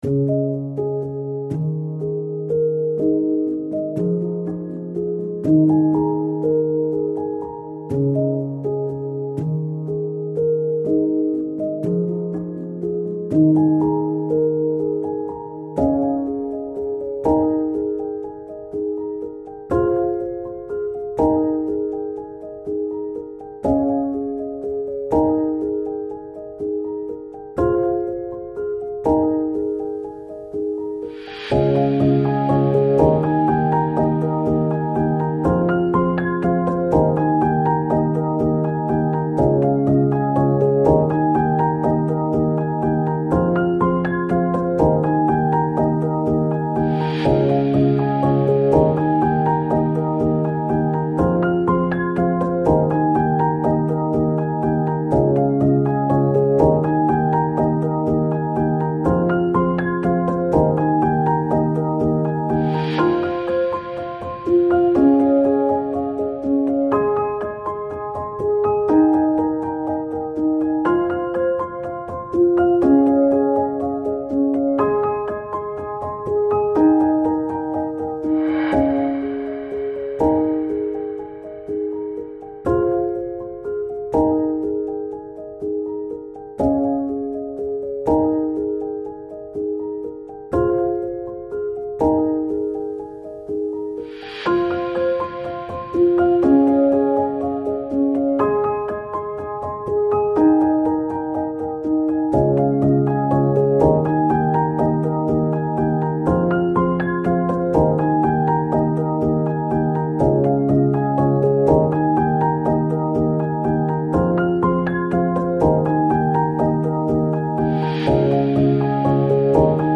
Electronic, Chillout, Piano Год издания